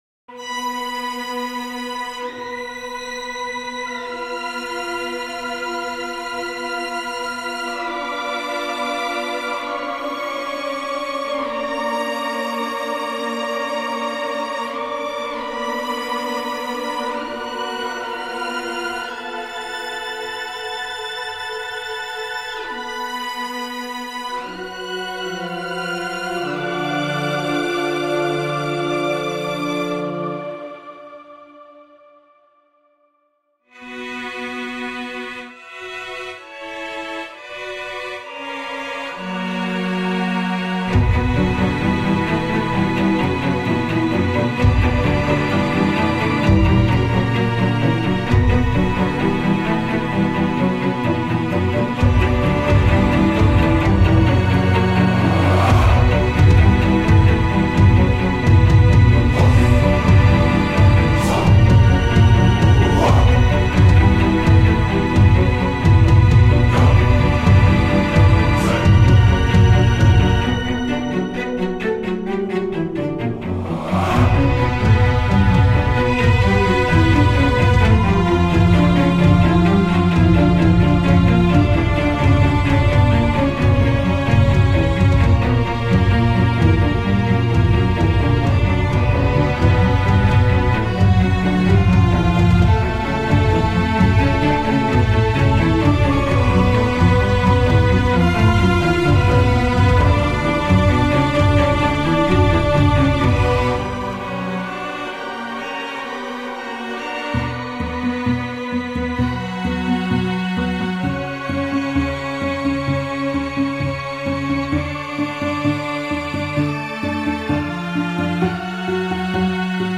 a creepy composition